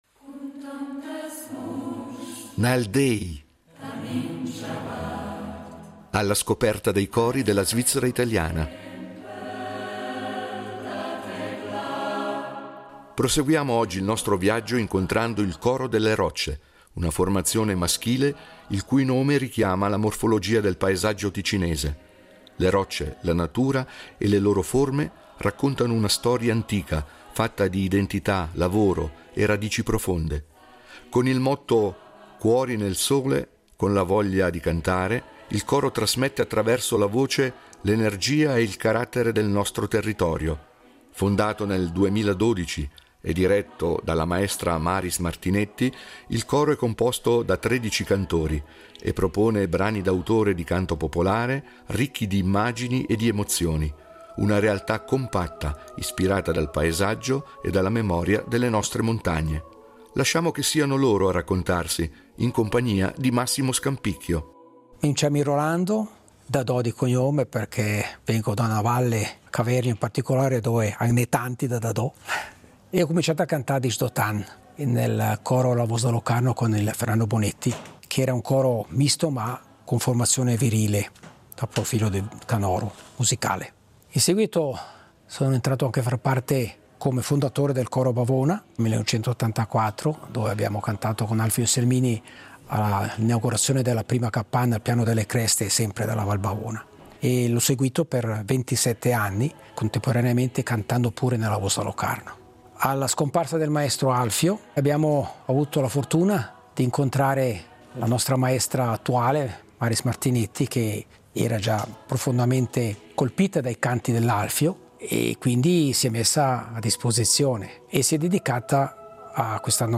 Nal déi, cori della svizzera italiana
è una formazione maschile di tredici cantori
brani d’autore di canto popolare ricchi di immagini ed emozioni